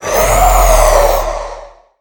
assets / minecraft / sounds / mob / wither / idle3.ogg